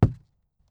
ES_Walk Wood Creaks 17.wav